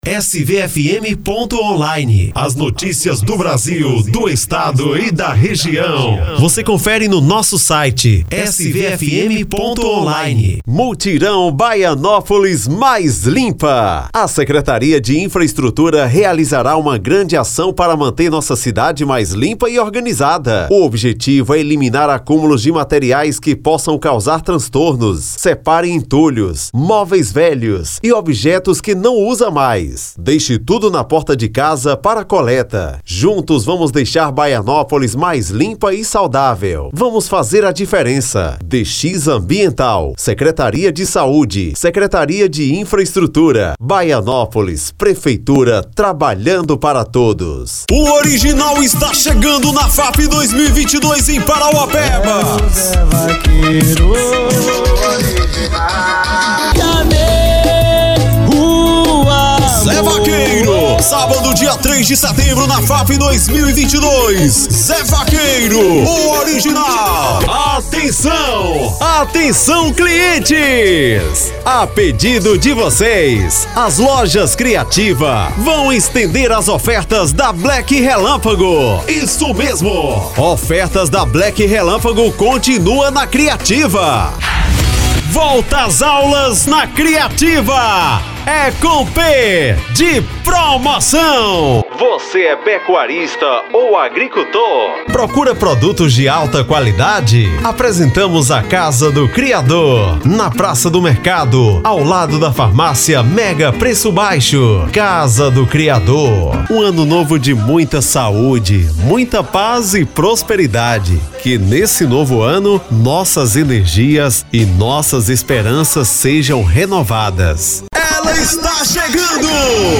Estilo(s):
Padrão
Impacto
Animada
Caricata